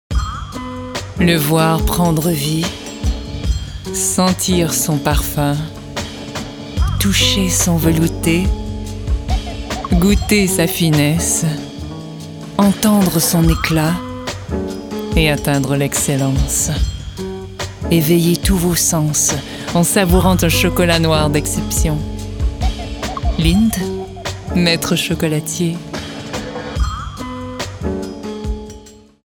Timbre Grave - Médium
Lindt - Sensuelle/sexy - Posée - Français soutenu /
Annonceuse - Fictif 2023 0:29 1 Mo